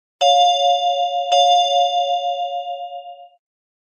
News-Alert02-2-C.ogg